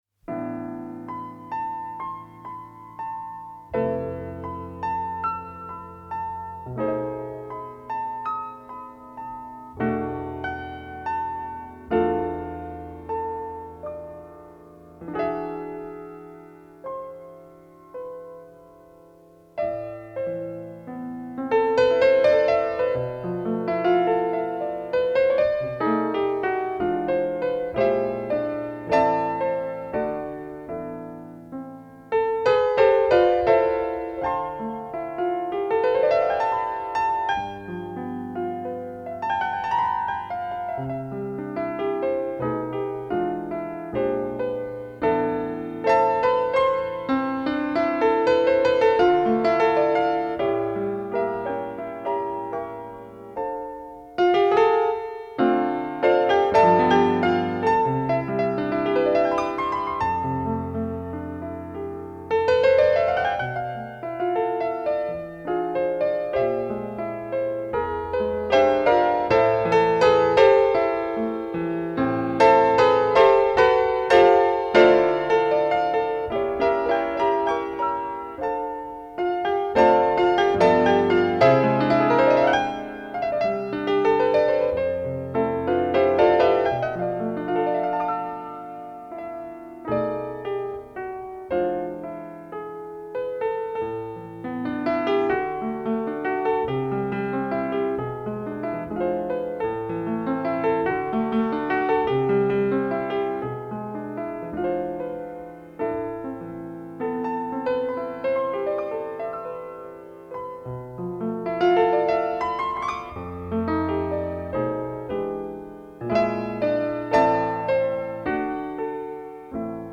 піаніст